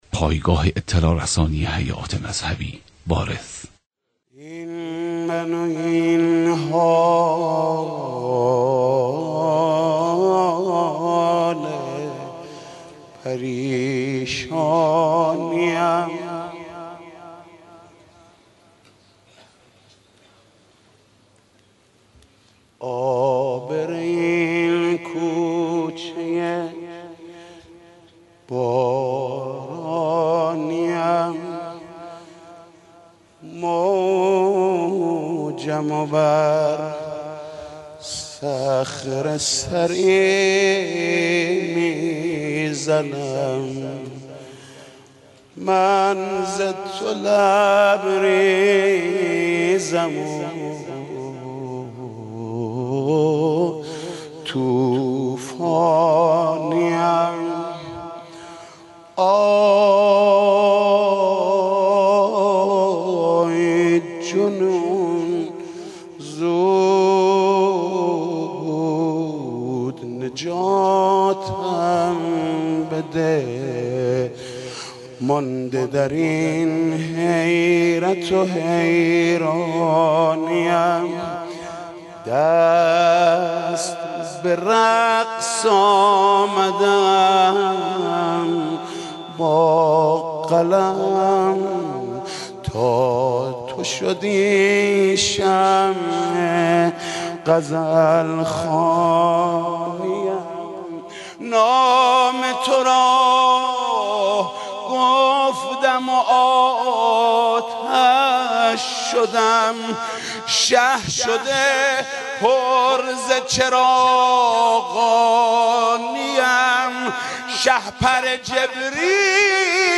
مولودی حاج محمود کریمی به مناسبت میلاد با سعادت امام زمان (عج)
دانلود صوت حاج محمود کریمی ولادت امام زمان عج هیئت رایة العباس ع وارث اخبار مرتبط انقلاب درونی مناجات خوان معروف تهران نماز عید فطر چگونه است؟